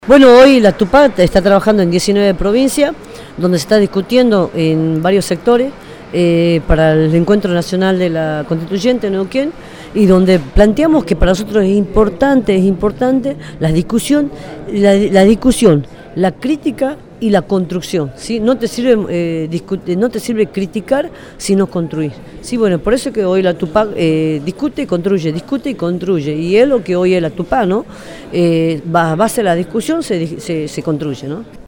Voces hacia la Constituyente Qué es la Constituyente y cómo la vamos construyendo por Milagro Sala El 15 de septiembre de 2009 Milagro Sala, referente de la Organización Barrial Tupac Amaru, presentes en la reunión organizativa de Rosario, brindó su opinión sobre qué es y qué significa la Constituyente y cómo la construyen territorialmente desde la Tupac.